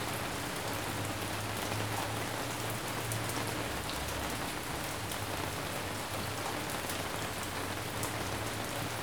Rain.wav